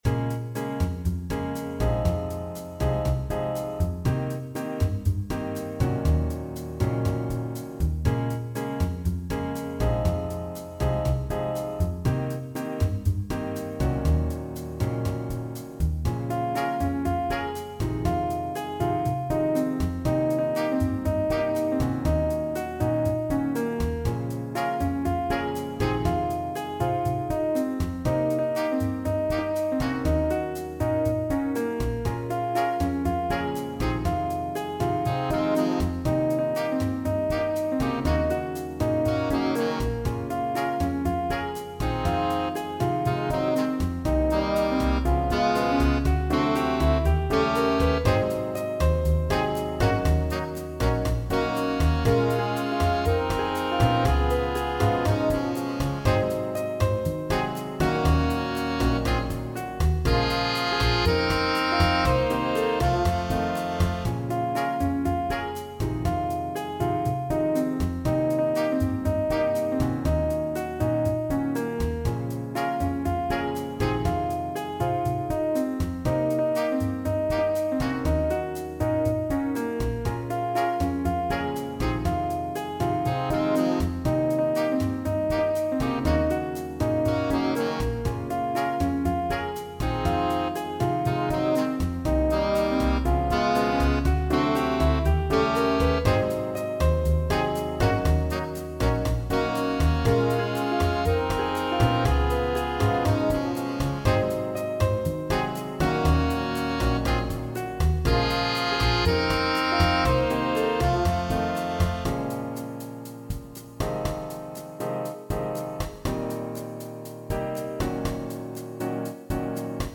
Seven-piece arrangements with improvisation
Vocal, Trumpet, Sax, Trombone, Piano, Bass, Drums
All audio files are computer-generated.